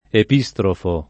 [ ep &S trofo ]